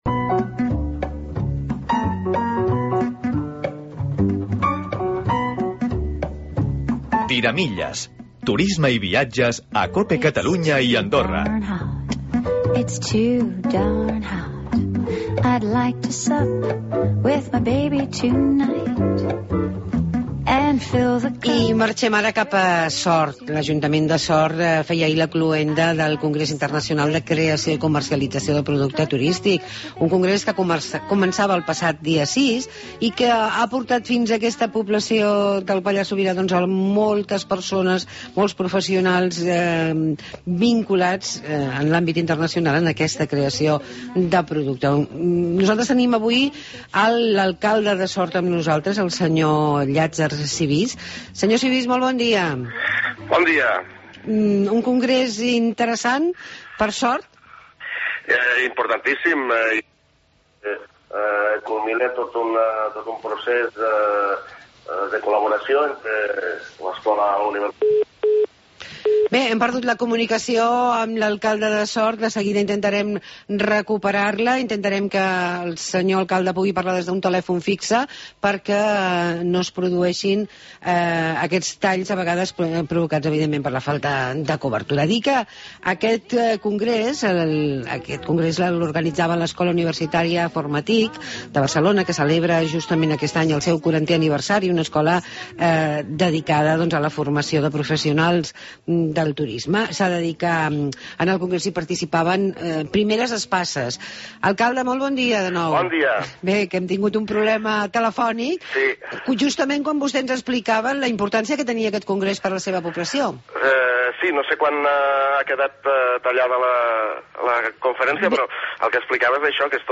A Sort trobem el Congrés internacional de Creació i Comercialització de Productes Turístics. Hem parlat amb Llatzer Sibís, Alcalde